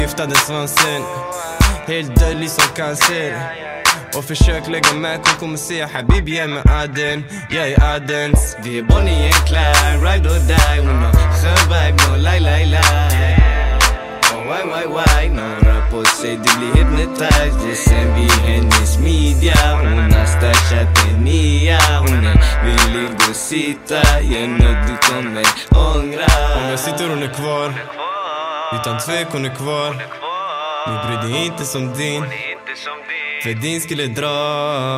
Жанр: Иностранный рэп и хип-хоп / Рэп и хип-хоп
# Hip-Hop